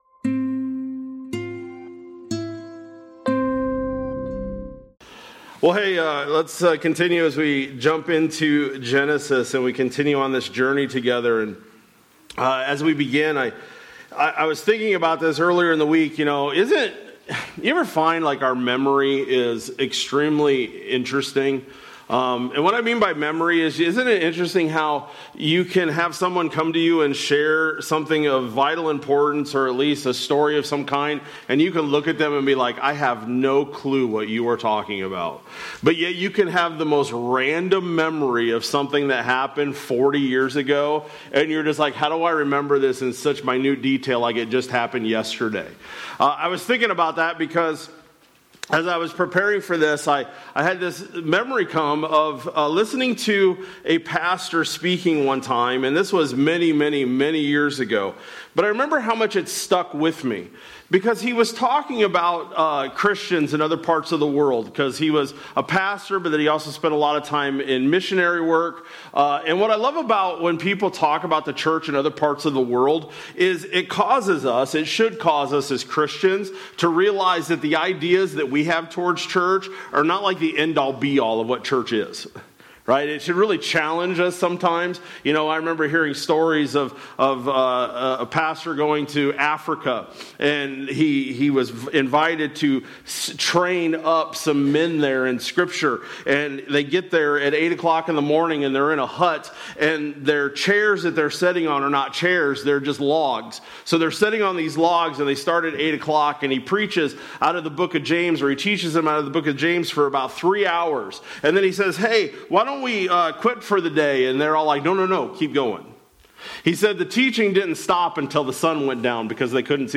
Jan-18-25-Sermon-Audio.mp3